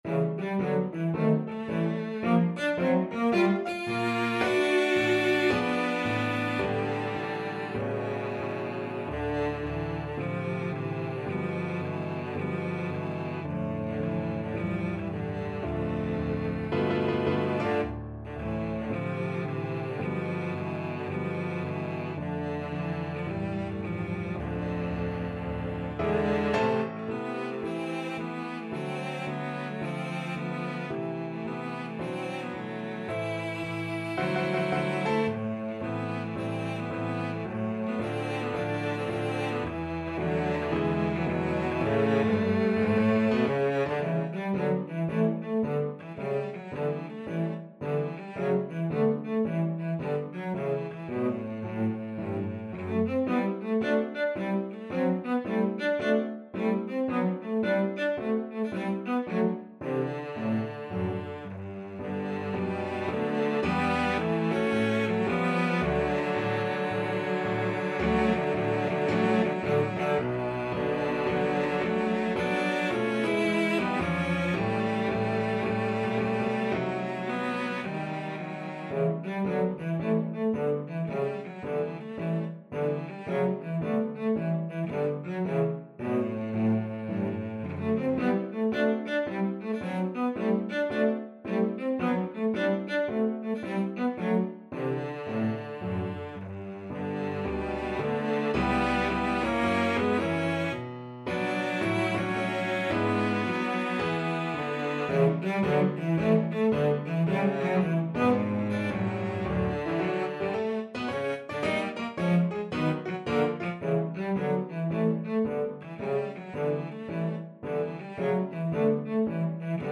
4/4 (View more 4/4 Music)
Moderato =110 swung